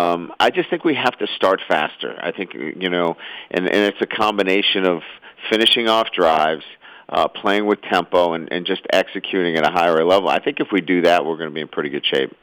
Brian-Kelly-Kirby-Smart-SEC-Teleconference-22.wav